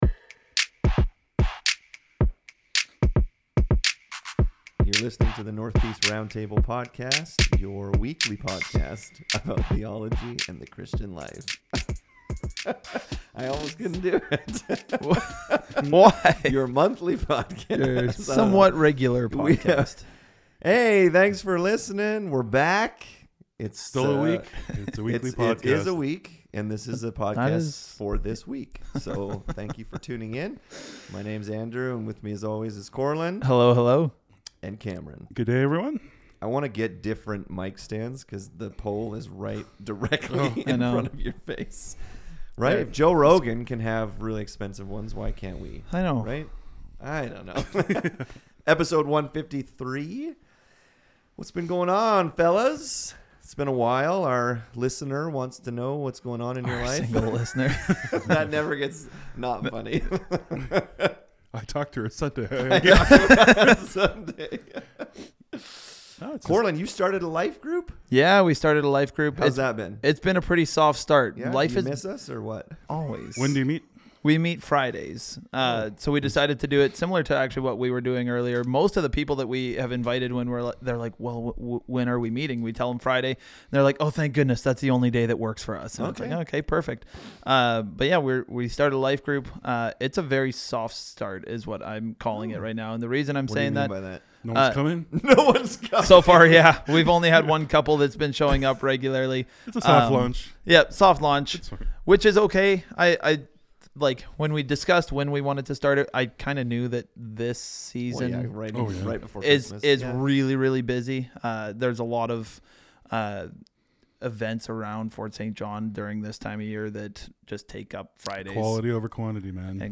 In this episode the guys talk about the difference between cultural practices and pagan worship. Should Christians redeem certain aspects of culture?